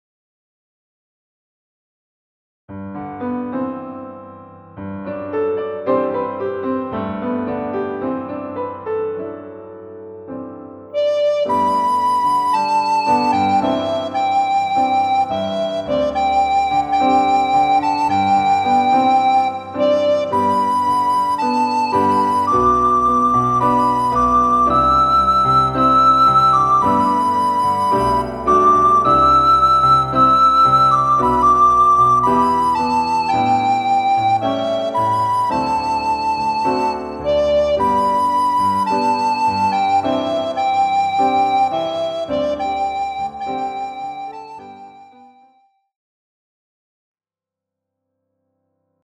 Version 1    for Recorder, Flute, Oboe or Violin
Key: G major (pentatonic)